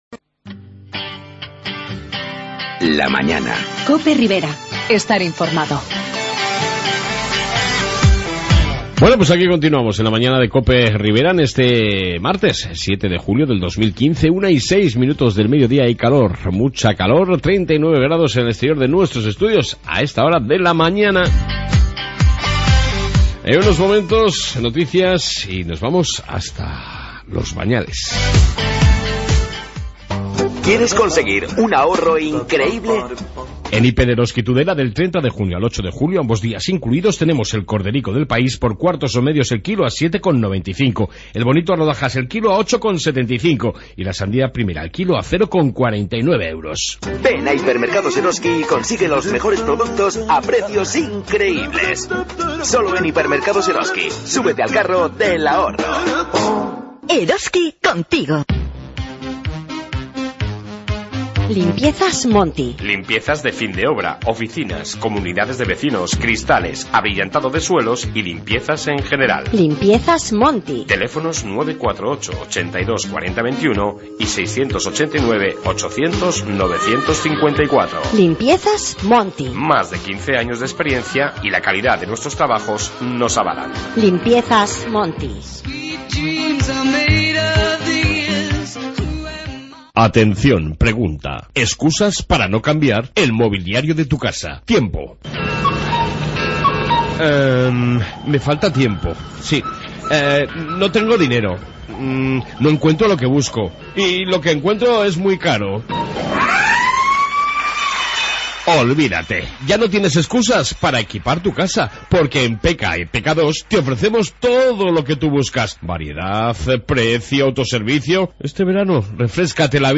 Noticias Riberas y Entrevista